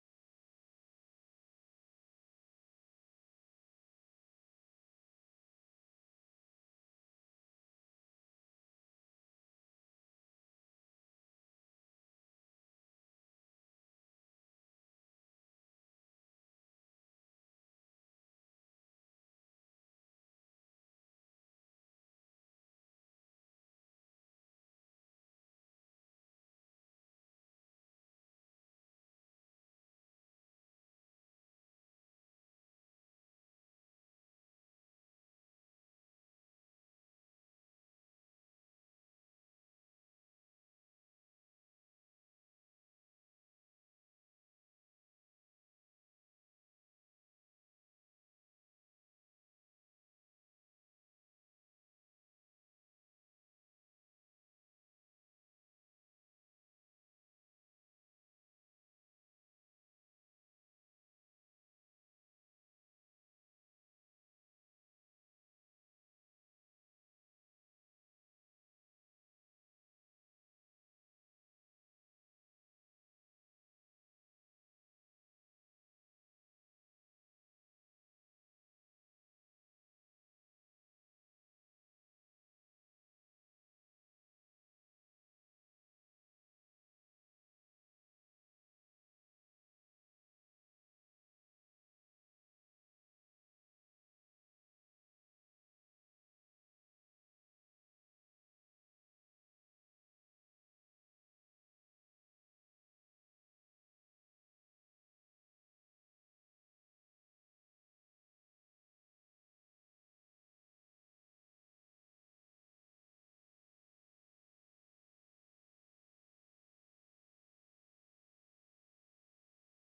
John-12_1-19-Opportunity-Sermon-Audio-CD.mp3